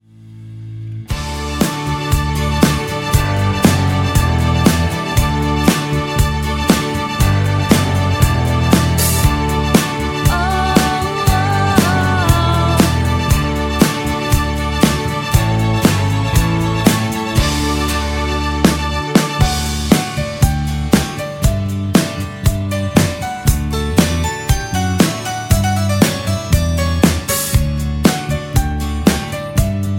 Backing track files: 1980s (763)